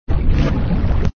6_rumble.wav.mp3